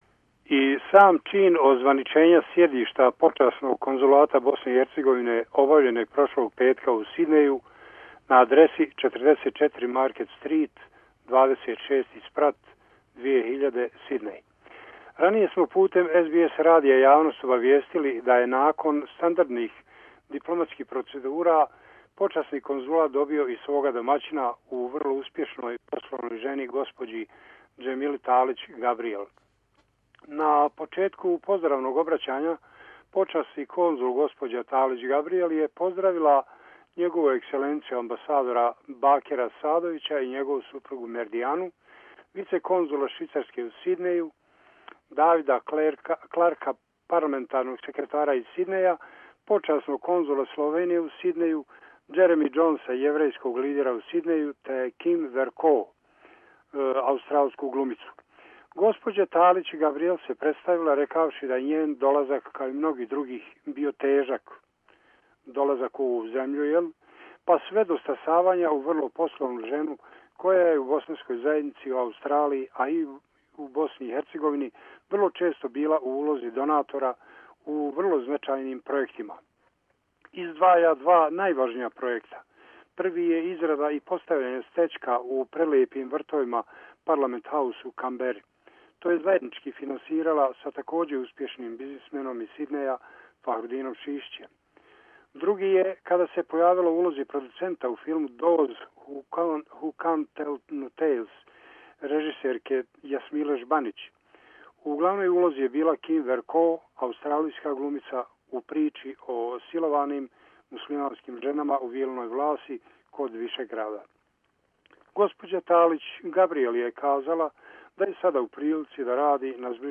Mrs. Demila Gabriel became Honorary Consul of Bosnia and Herzeovina Report from Sydney